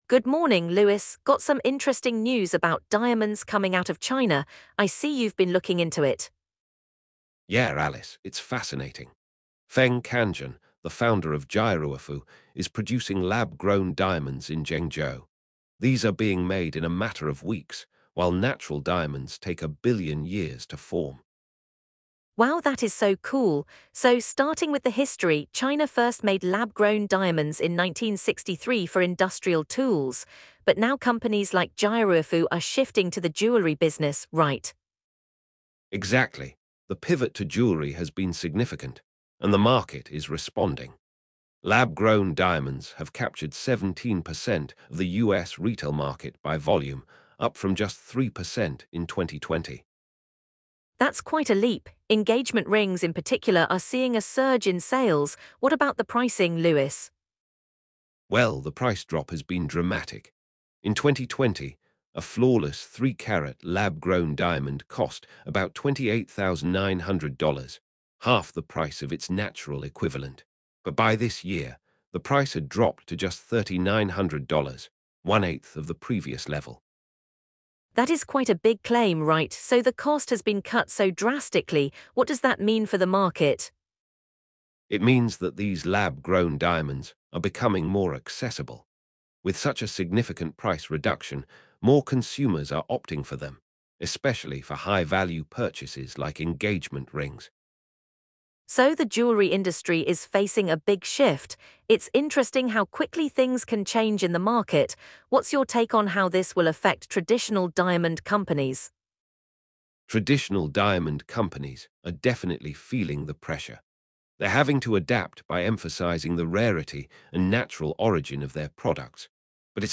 Learn about the dramatic price drops, the impact on engagement ring sales, and how traditional diamond companies are responding to the challenge. The conversation also touches on sustainability and the future of the diamond industry as it faces a technological revolution.